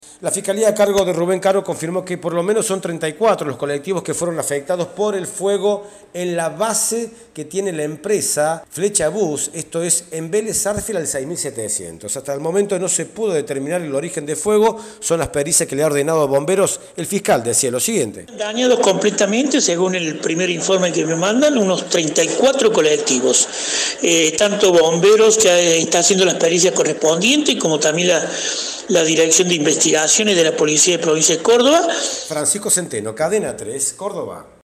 Informes